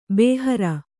♪ bēhara